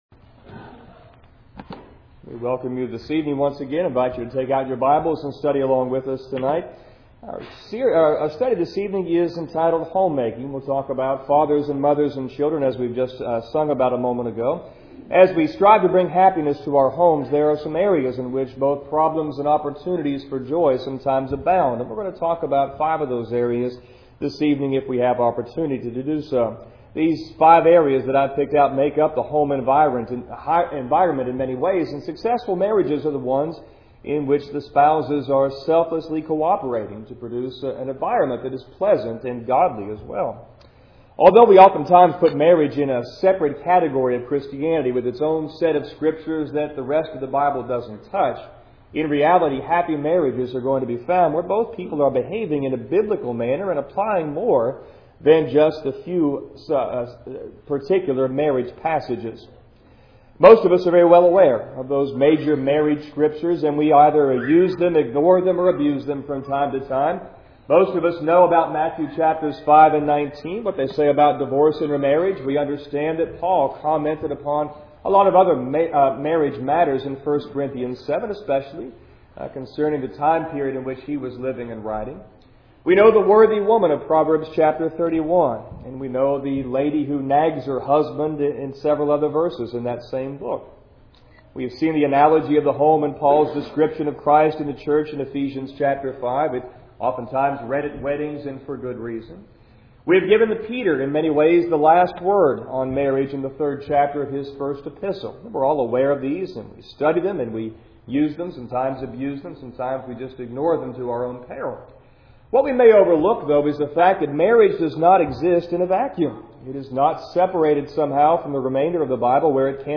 Home Making - Tuesday Evening's sermon